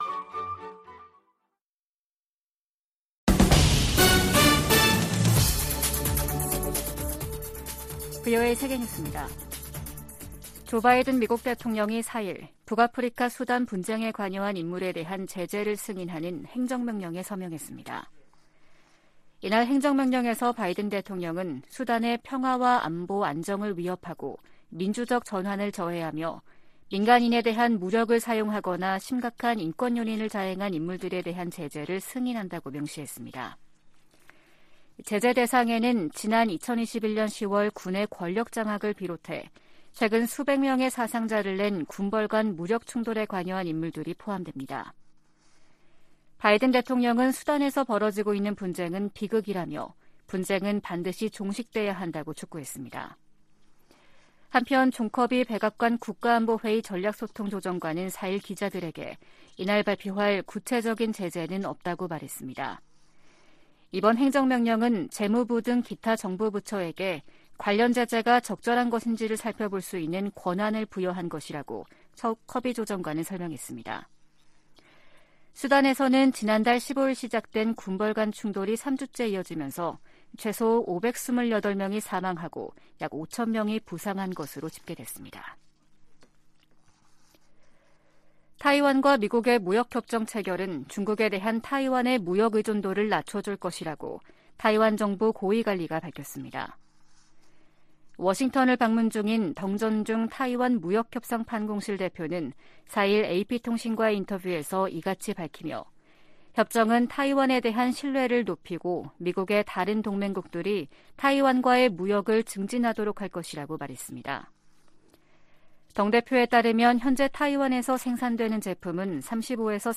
VOA 한국어 아침 뉴스 프로그램 '워싱턴 뉴스 광장' 2023년 5월 5일 방송입니다. 한일·미한일 정상회담이 이달 중 연이어 개최 될 예정입니다. 미 국무부가 기시다 후미오 일본 총리의 한국 방문 계획을 환영했습니다.